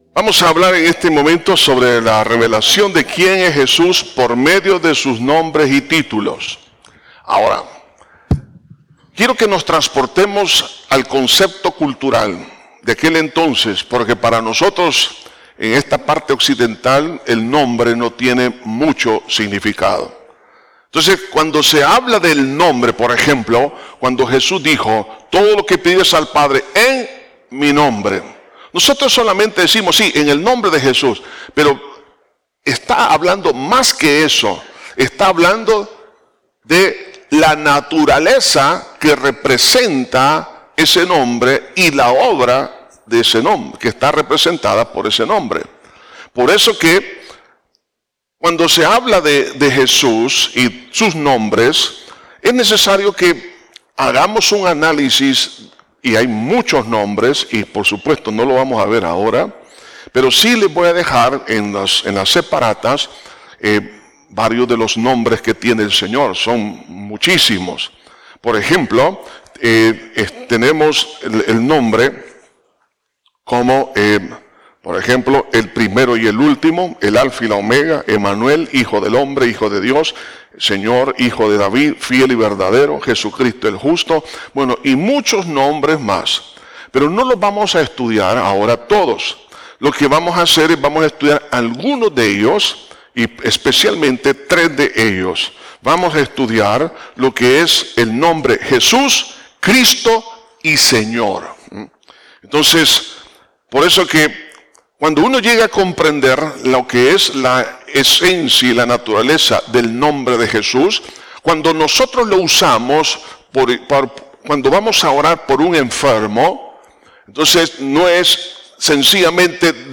Estudio de Cristologia